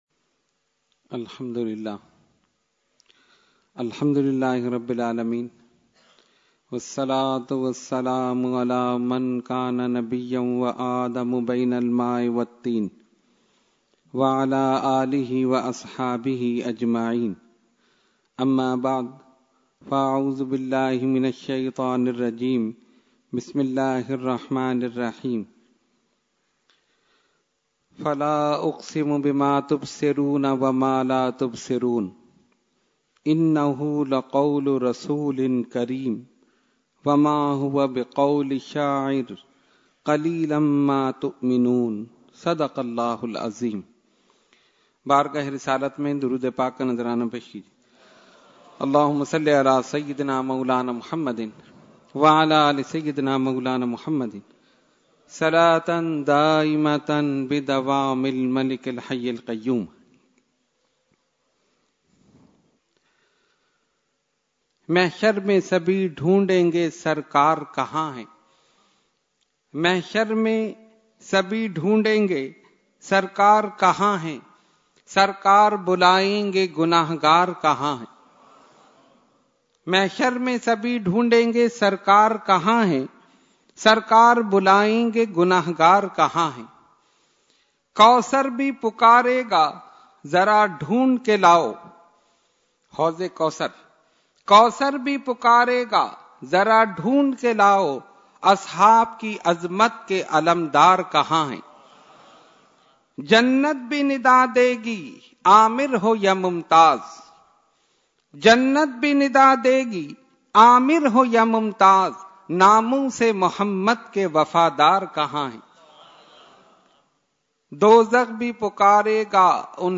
Weekly Tarbiyati Nashist held on 9/3/2014 at Dargah Alia Ashrafia Ashrafabad Firdous Colony Karachi.